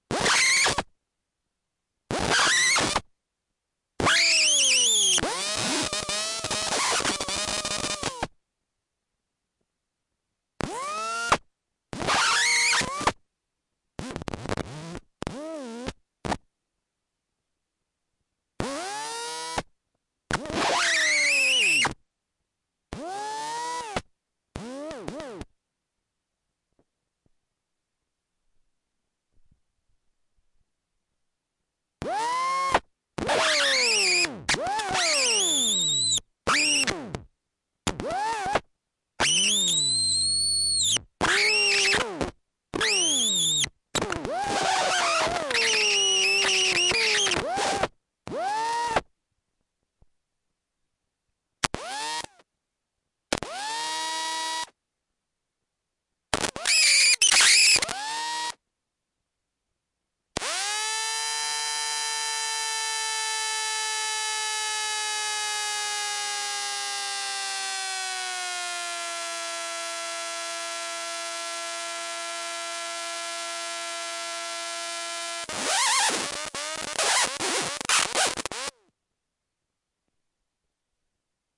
装修声冲击钻
描述：被楼下吵死了，实录
标签： 装修 冲击钻 电钻
声道立体声